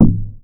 effect__clonk.wav